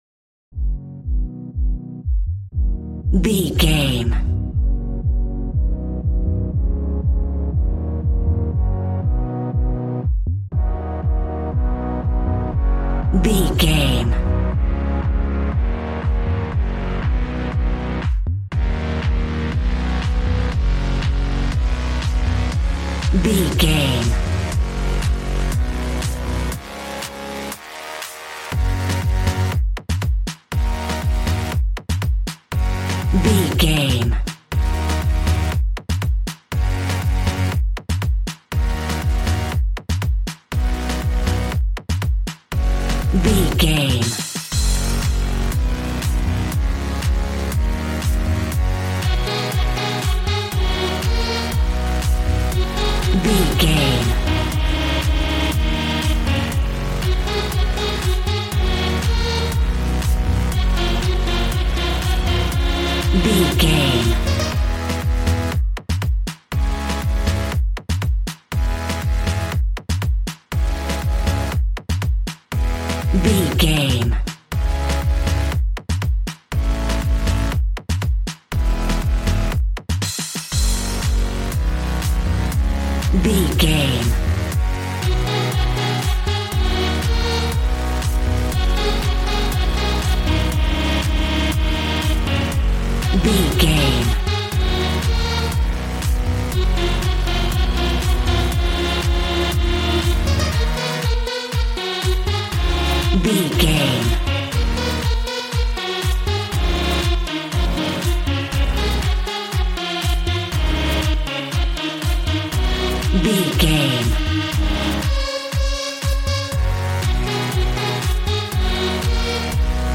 Ionian/Major
G♭
Fast
groovy
energetic
synthesiser
drums